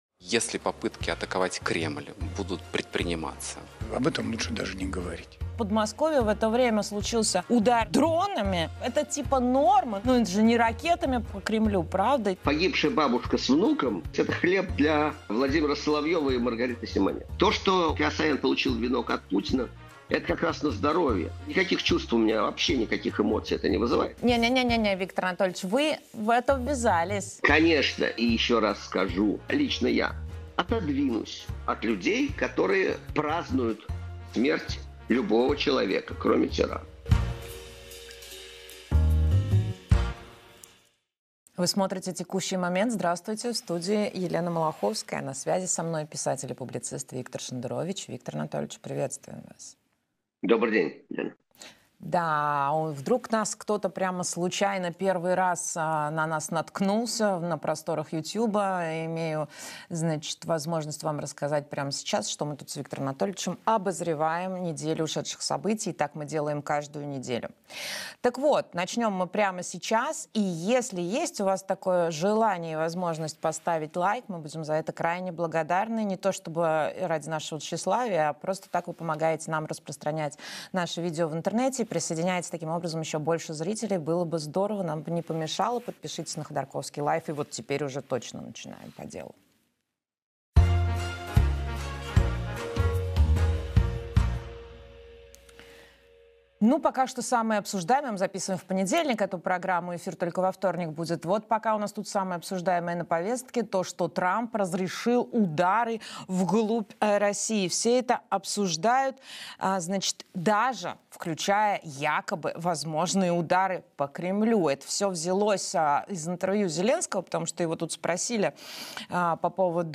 Виктор Шендерович писатель